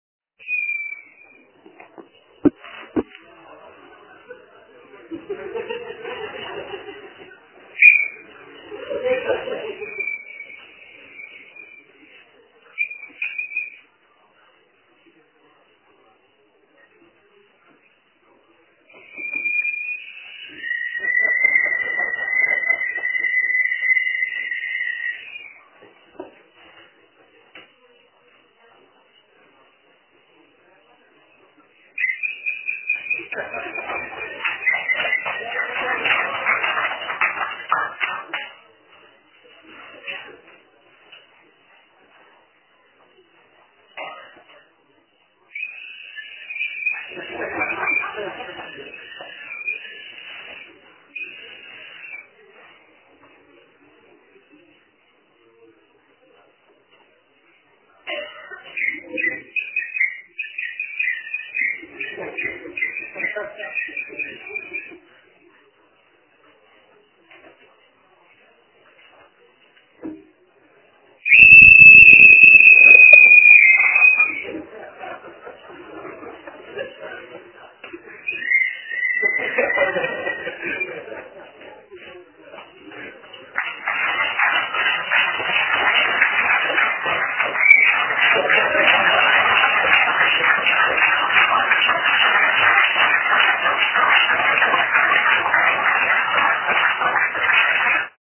projev